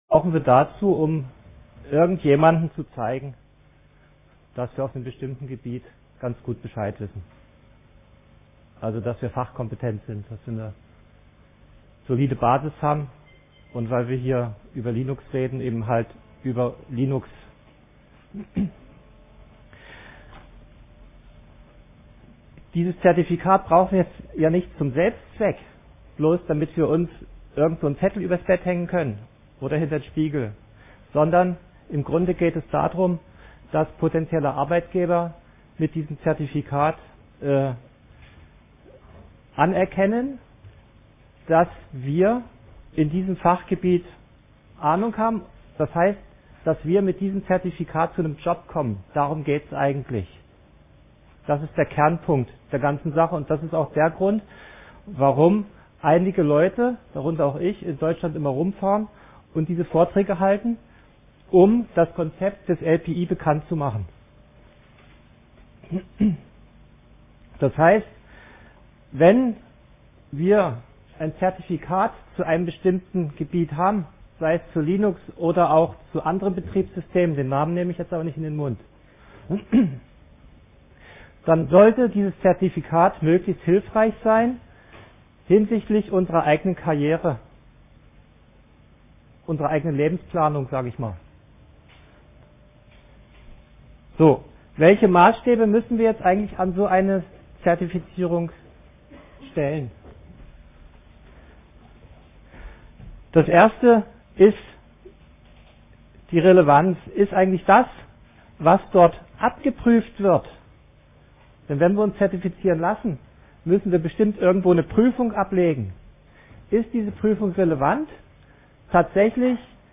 Samstag, 10:00 Uhr im Raum V3 - Gesellschaft